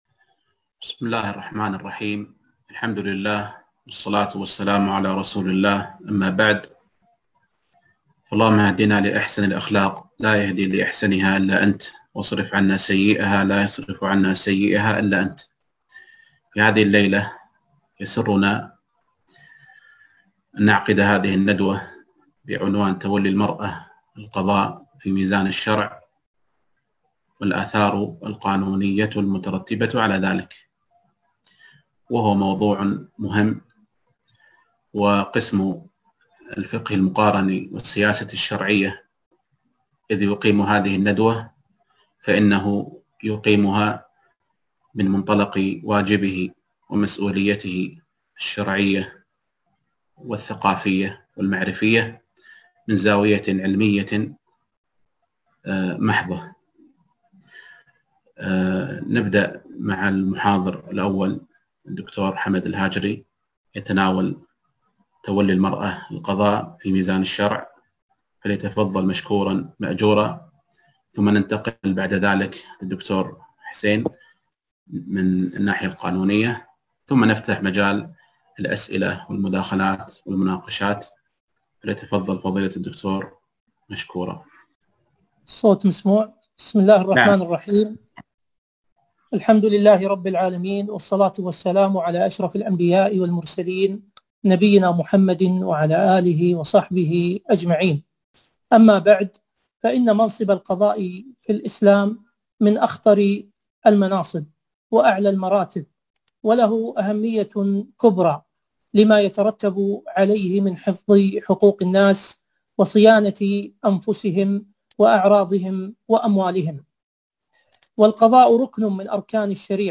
ندوة